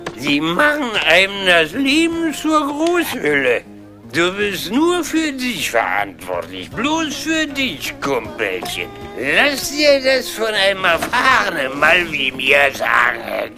- Lush (Besoffener in der Bar) -